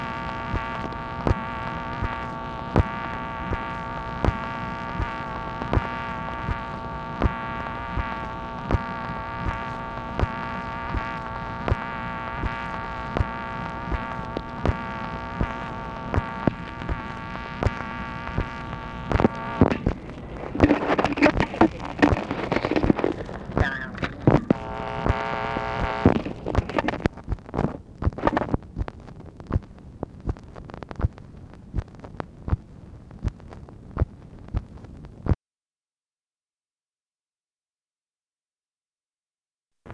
MACHINE NOISE, December 27, 1967
Secret White House Tapes | Lyndon B. Johnson Presidency